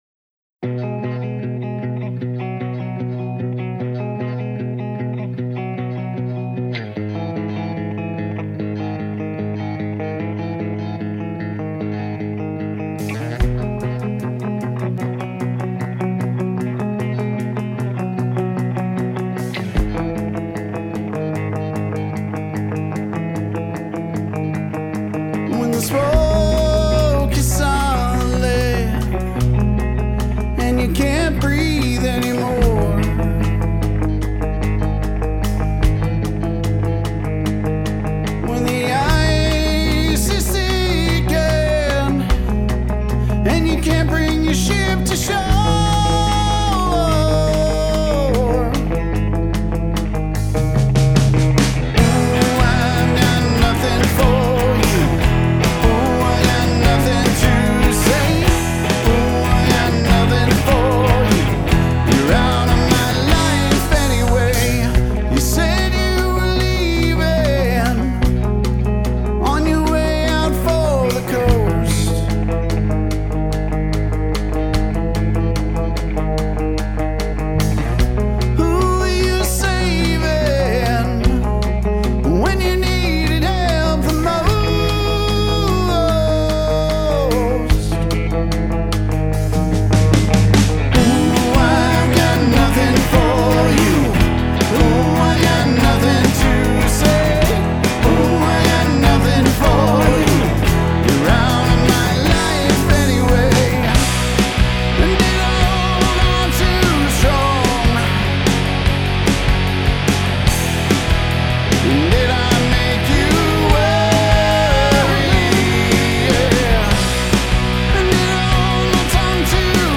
Size: 3-6 performers